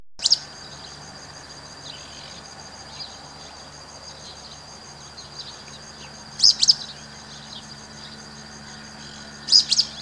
Pijuí Cola Parda (Synallaxis albescens)
Nombre en inglés: Pale-breasted Spinetail
Localización detallada: Camino Rural
Condición: Silvestre
Certeza: Vocalización Grabada